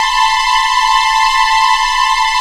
SCARY C4.wav